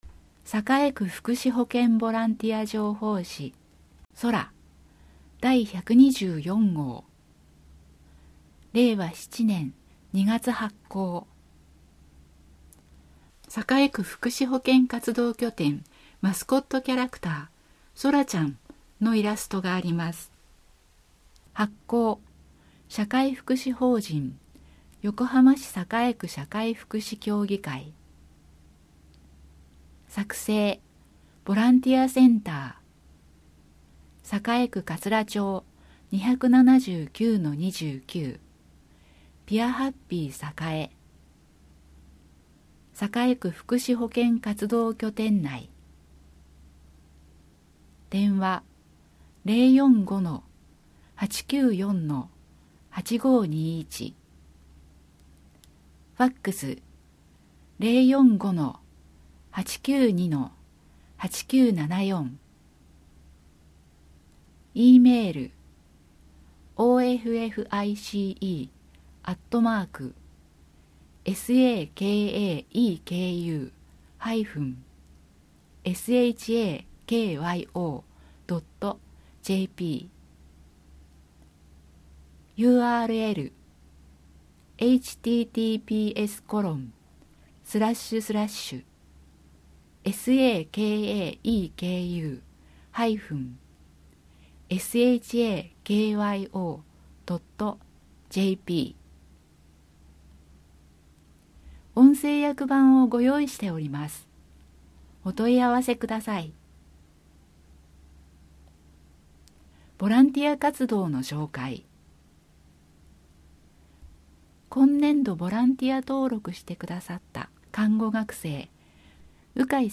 ボランティア情報誌「そら」 そら 124号（PDF） 音声訳版 124号（MP3） 発行日 2025年2月1日 そら 123号（PDF） 音声訳版 123号（MP3） 発行日 2024年8月1日 そら 122号（PDF） […]